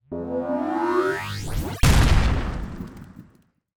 etfx_explosion_chargeup2.wav